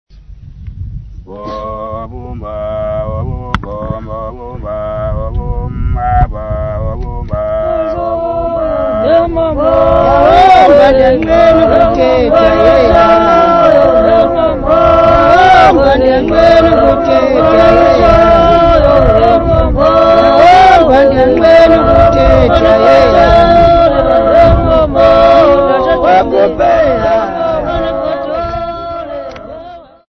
Folk music
Field recordings
Xhosa women use Umngqokolo techniques with normal voices, performing traditional Xhosa music with clapping accompaniment.
7.5 inch reel